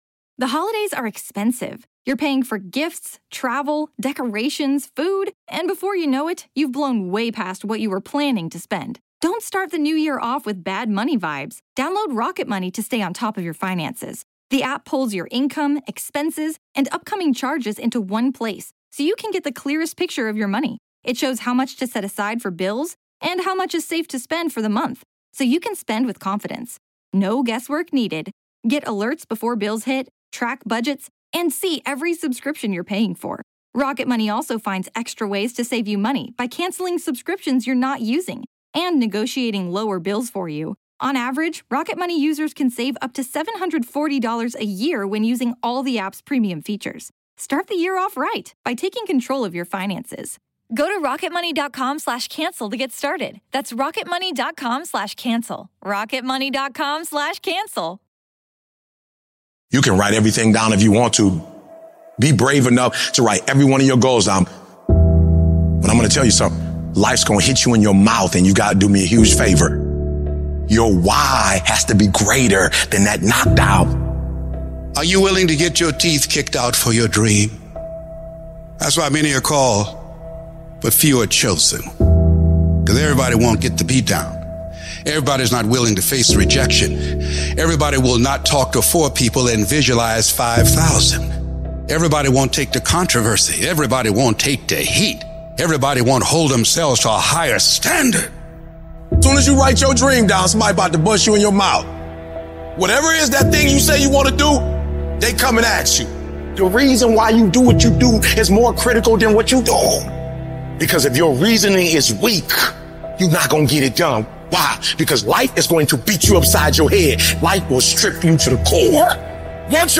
Speakers: Eric Thomas, Les Brown, Prince Ea.